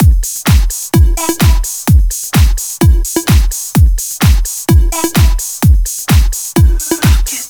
VFH1 128BPM Moonpatrol Kit 1.wav